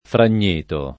fran’n’%to] (ant. Fragnito [fran’n’&to]) top. (Camp.) — due comuni: Fragneto l’Abate [fran’n’%to l ab#te]; Fragneto Monforte [fran’n’%to monf0rte] — sim. il cogn. Fragnito